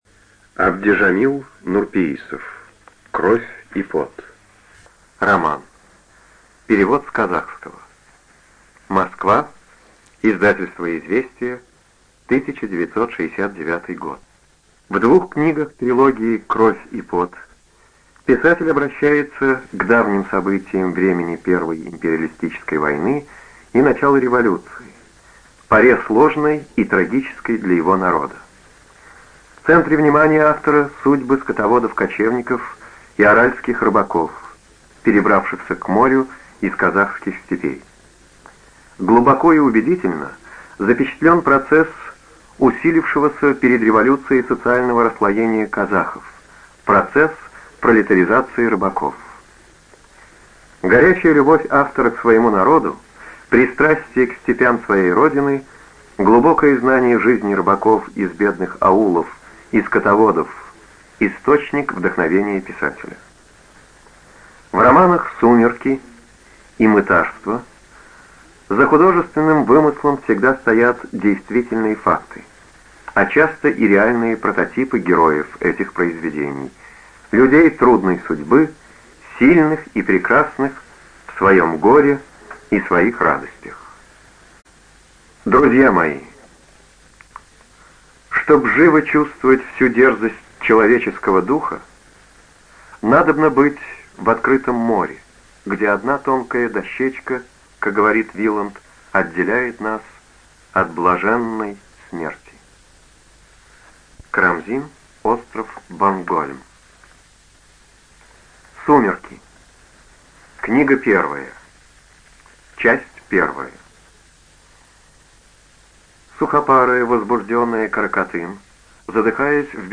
ЖанрИсторическая проза
Студия звукозаписиЛогосвос